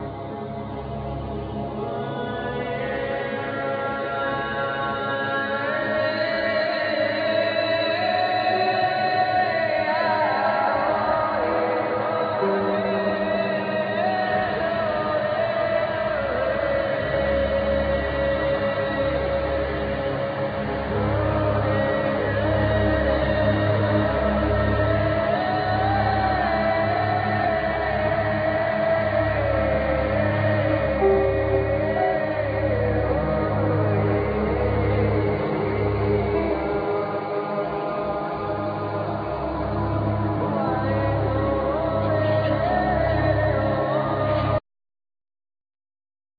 Keyboards,Synthesizers,Percussion,Vocal